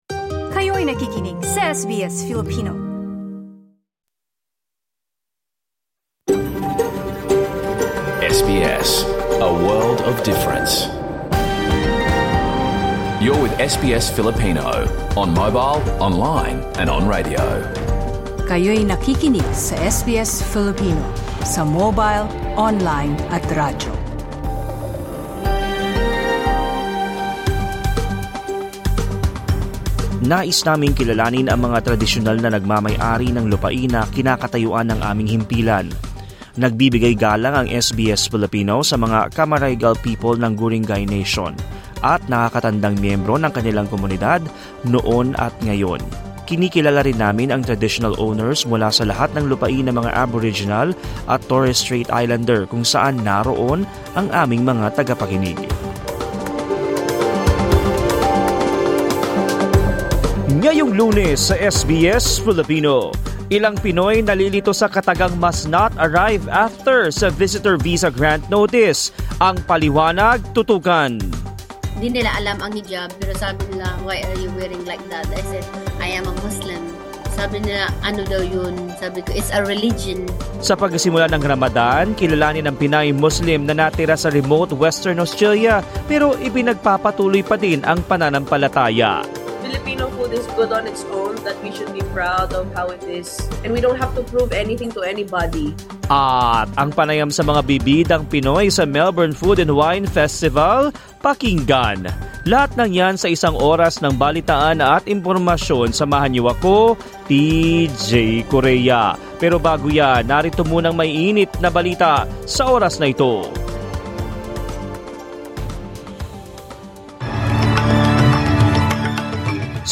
Sa pagsisimula ng Ramadan, kilalanin ang Pinay Muslim na natira sa remote Western Australia pero ipinagpatuloy pa din ang pananampalataya. At ang panayam sa mga bibidang Pinoy sa Melbourne Food and Wine Festival.